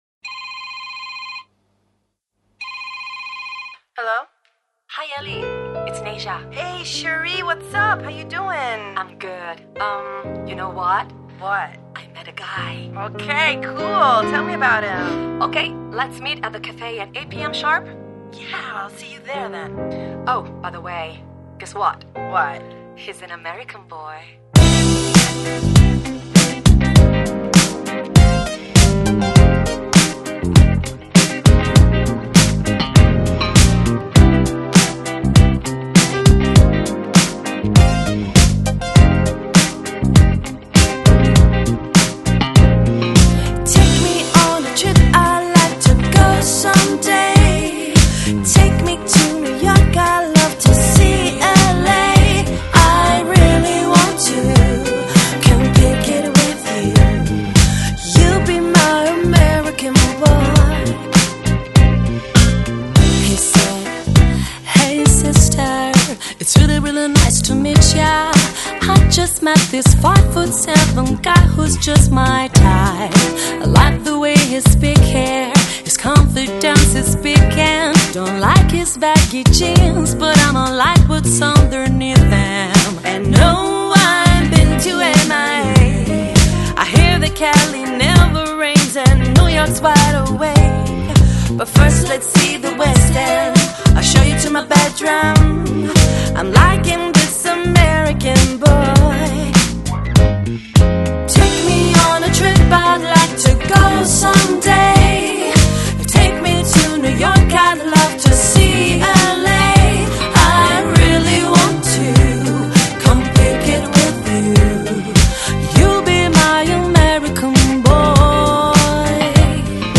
A Selection of Today's Hits in a New Lounge Version
Downtempo, Lounge, Nu Jazz, Easy Listening